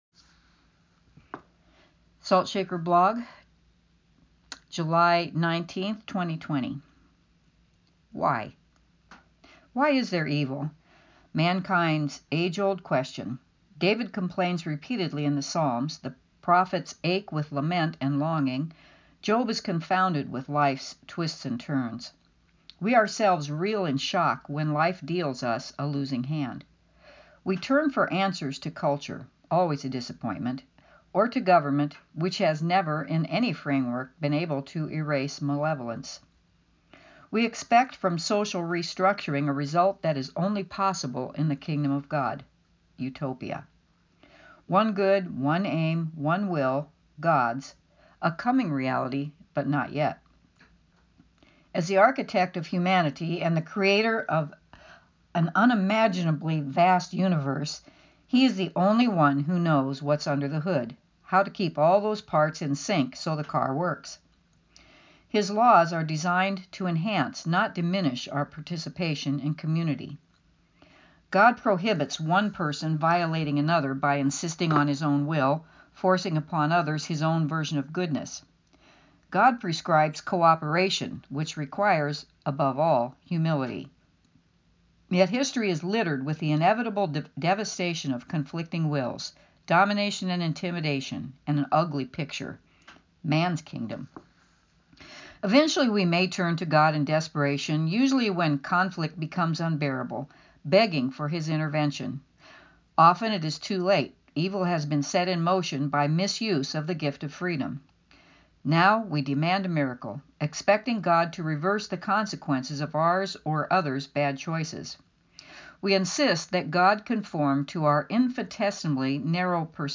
Audio version read by the author